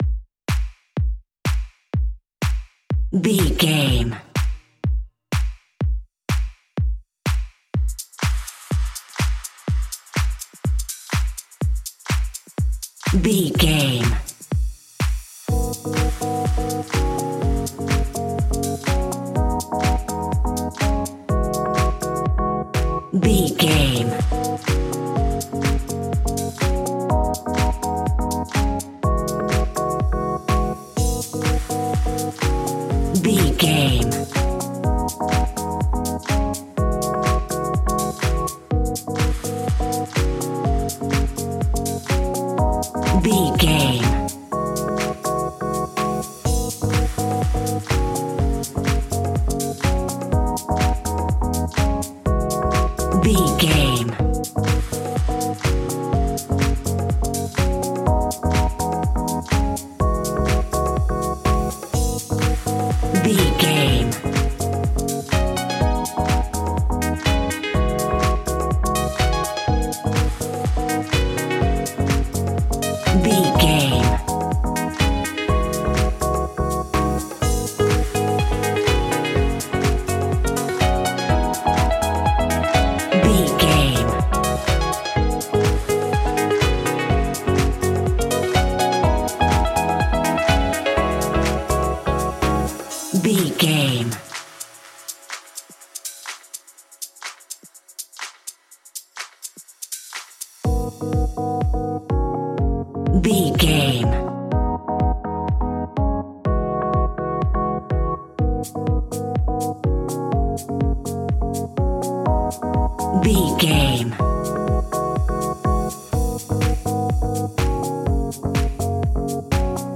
Aeolian/Minor
G#
groovy
uplifting
driving
energetic
bouncy
synthesiser
drum machine
electro house
instrumentals
synth bass